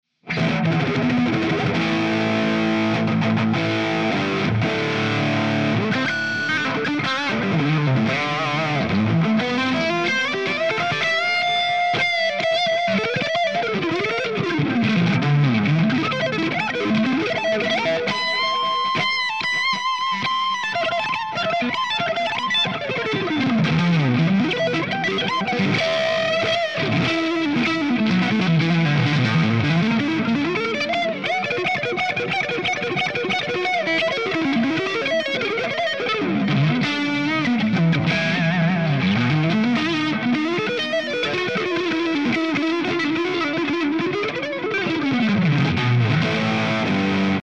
Improv
RAW AUDIO CLIPS ONLY, NO POST-PROCESSING EFFECTS